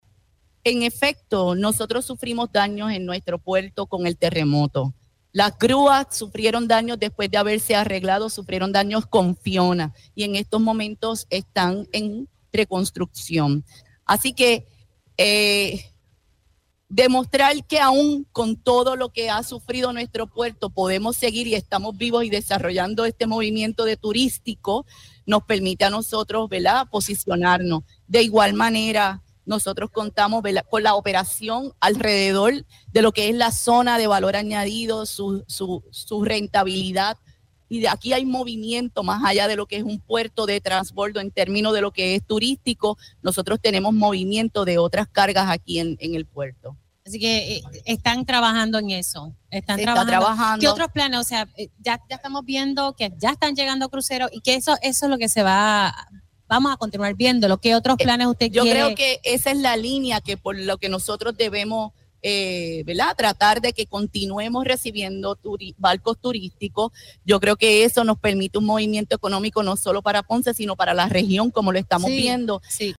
Por su parte, en entrevista para este espacio la alcaldesa Marlese Sifre se mostró emocionada con el acontecimiento.